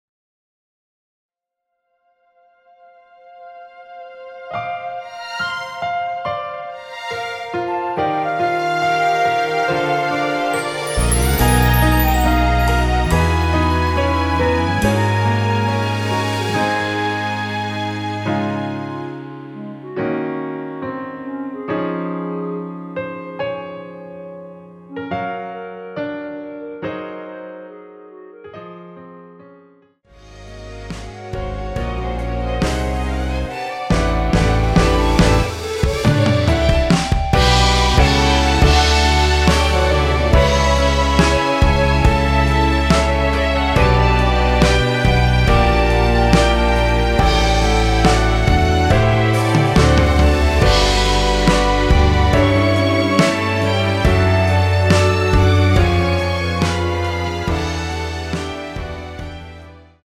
원키에서(+3)올린 멜로디 포함된 MR입니다.(미리듣기 확인)
Db
앞부분30초, 뒷부분30초씩 편집해서 올려 드리고 있습니다.
중간에 음이 끈어지고 다시 나오는 이유는